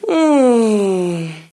voz nș 0161